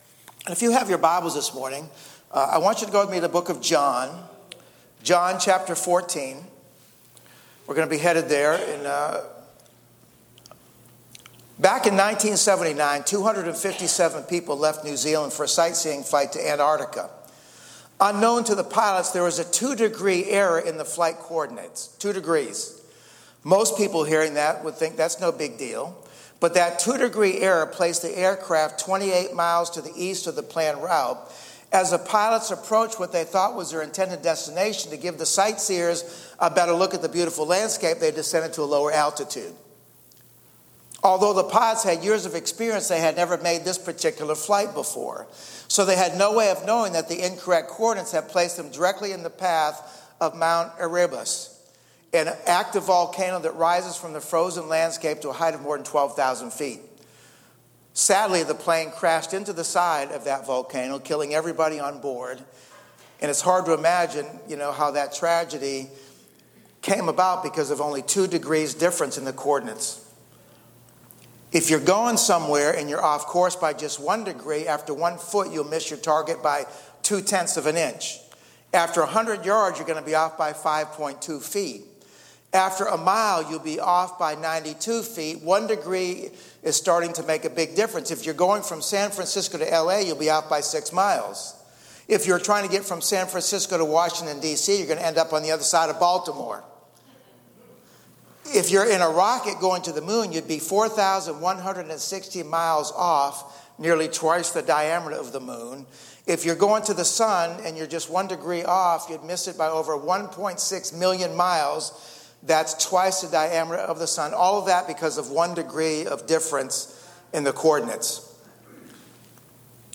Online-Church-May-11-AUDIO.mp3